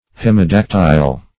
Search Result for " hemidactyl" : The Collaborative International Dictionary of English v.0.48: Hemidactyl \Hem`i*dac"tyl\, n. [See Hemi- , and Dactyl .] (Zool.) Any species of Old World geckoes of the genus Hemidactylus .
hemidactyl.mp3